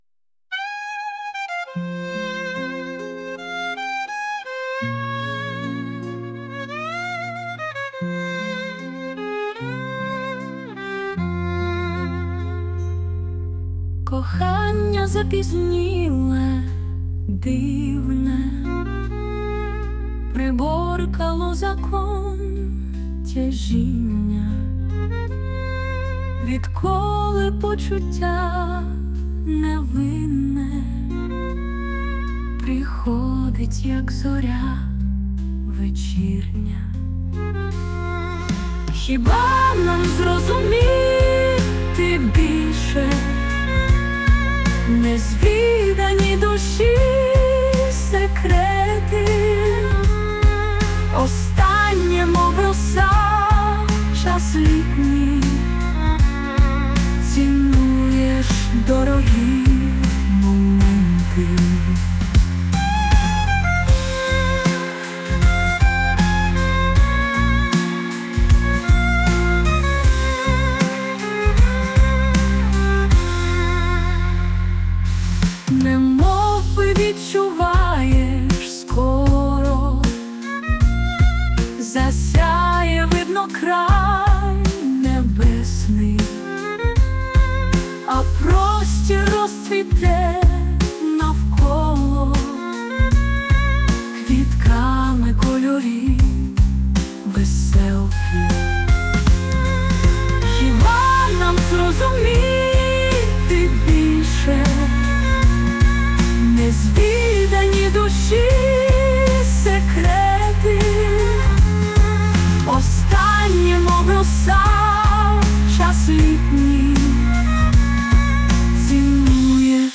SUNO AI